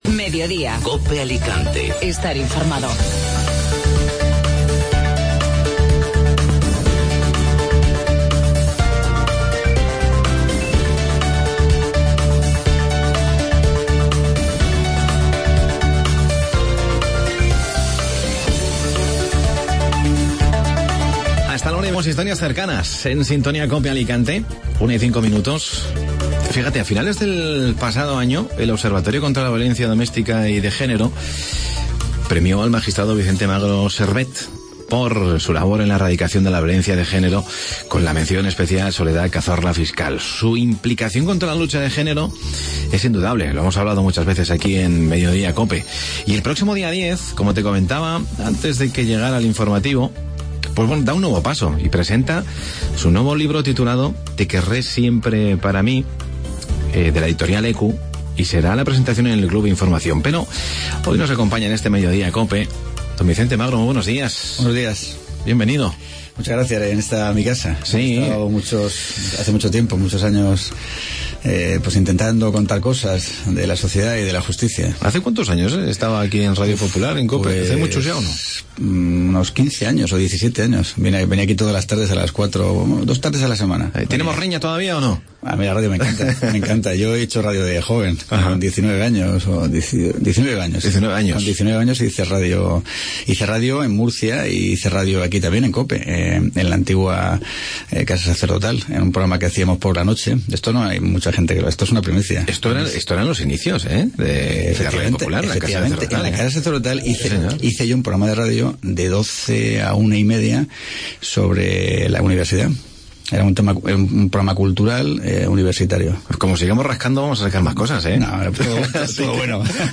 Entrevista a Vicente Magro